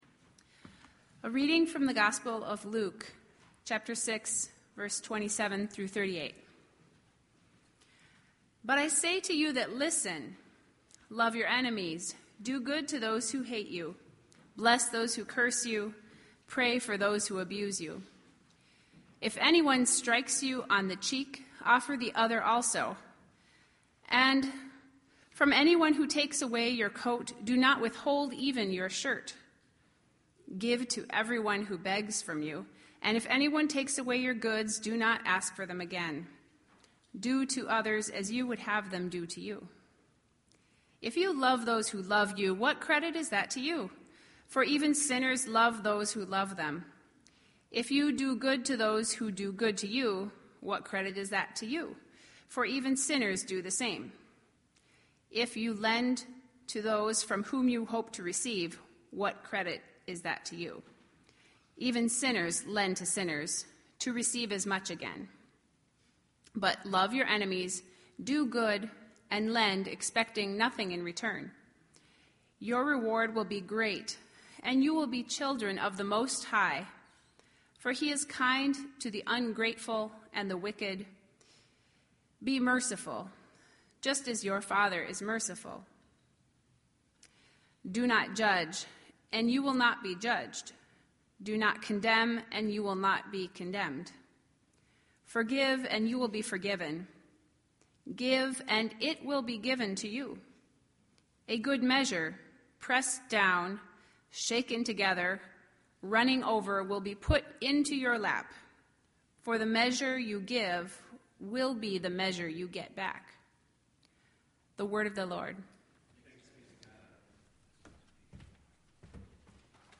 This entry was posted in Sermons .